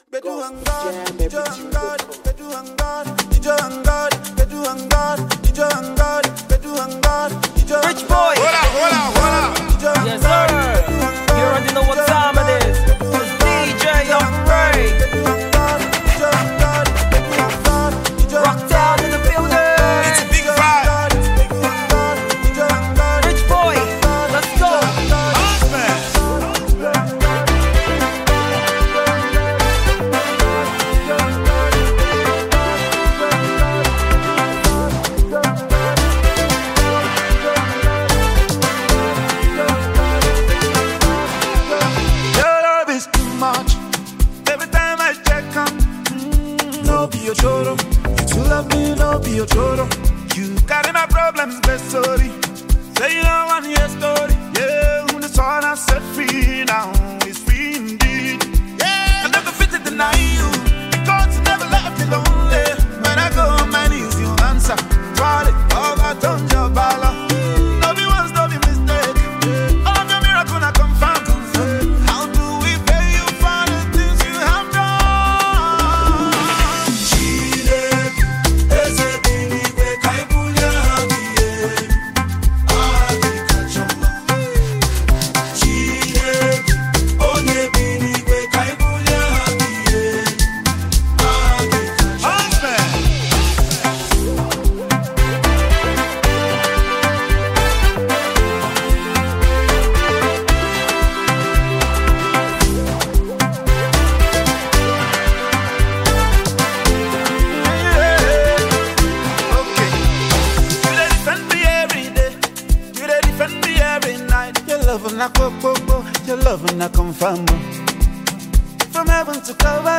New sound of praise